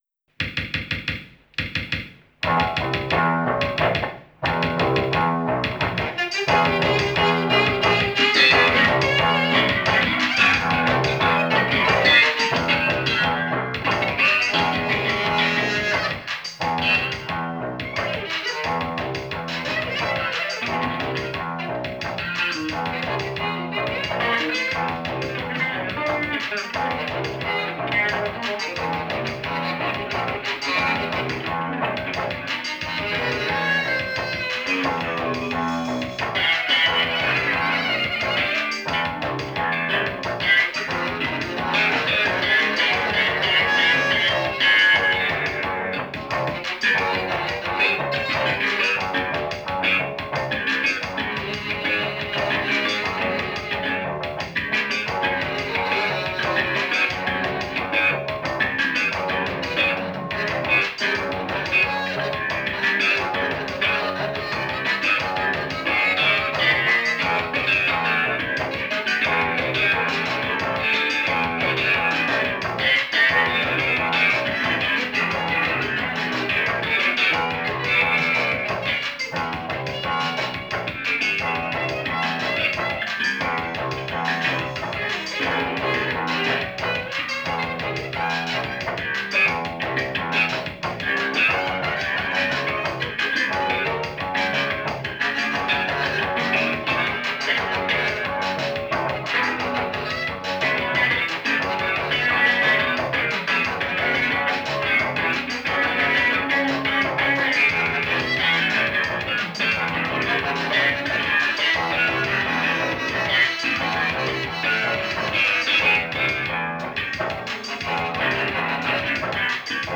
'86.4.27　盛岡・伊藤楽器3ホール
sax,per